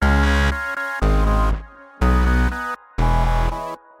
Tag: 60 bpm Electronic Loops Synth Loops 691.56 KB wav Key : Unknown